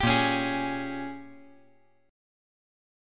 Listen to A#m13 strummed